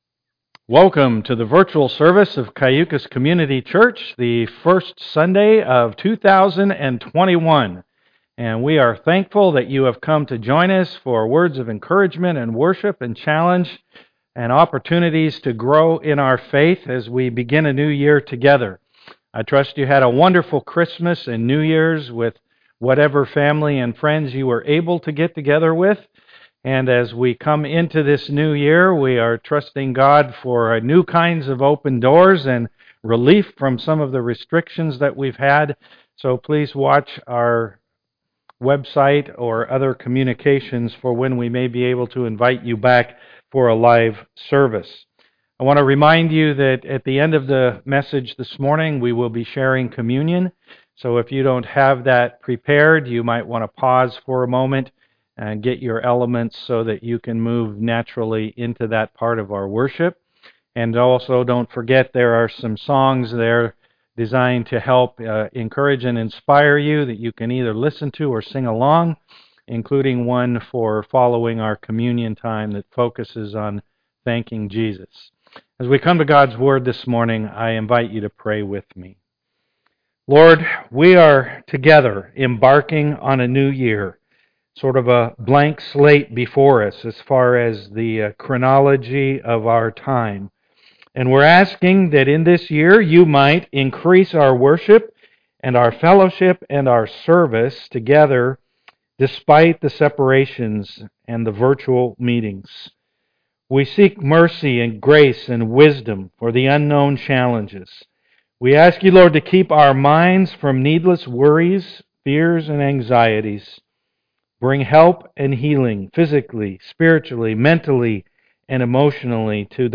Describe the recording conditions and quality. Passage: Psalm 71 Service Type: am worship Click on the links below to enjoy a time of worship prior to listening to the message.